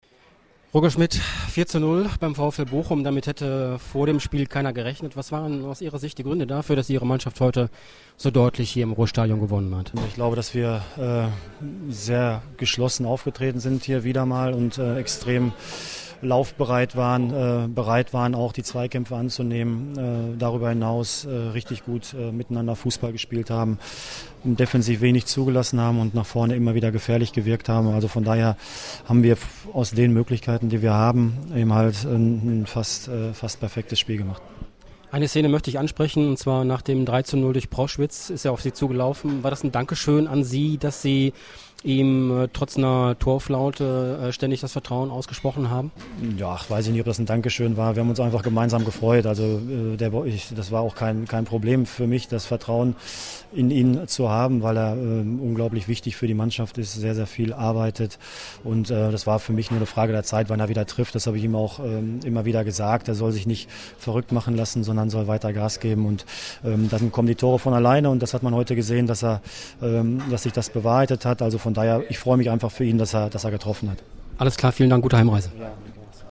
Audiokommentar
Chef-Trainer Roger Schmidt zum Spiel